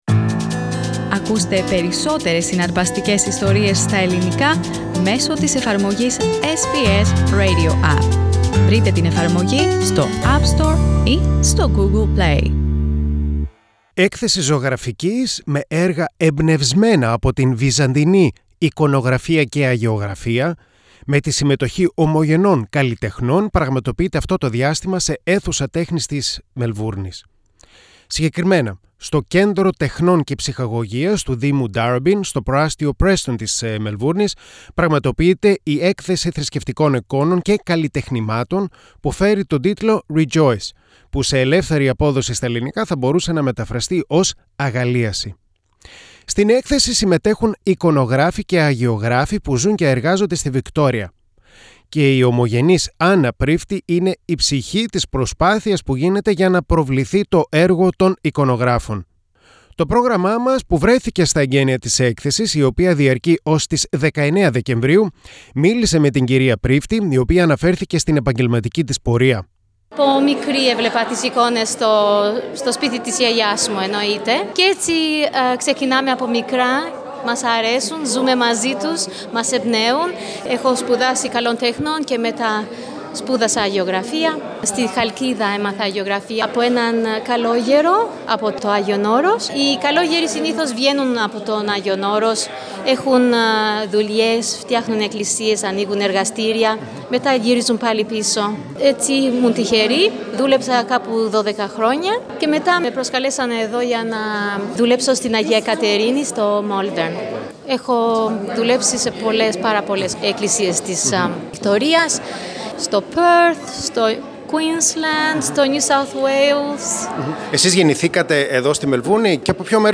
Έκθεση αγιογραφίας με έργα εμπνευσμένα από τις αγιογραφίες του Βυζαντίου με την συμμετοχή Ελληνοαυστραλών καλλιτεχνών πραγματοποιείται αυτό το διάστημα σε αίθουσα τέχνης της Μελβούρνης. To SBS Greek βρέθηκε στα εγκαίνια της έκθεσης και μίλησε με δυο καλλιτέχνες.